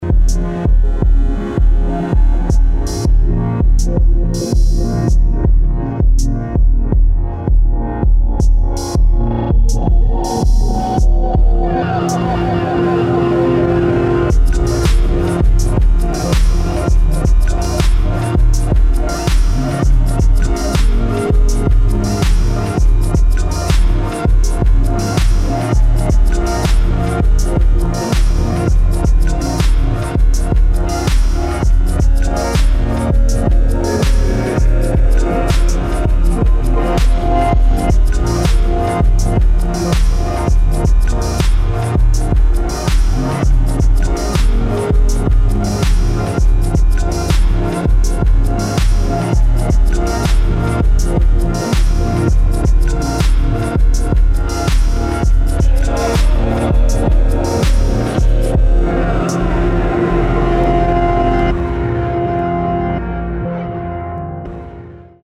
[ DOWNBEAT / ELECTRONIC / DUBSTEP ]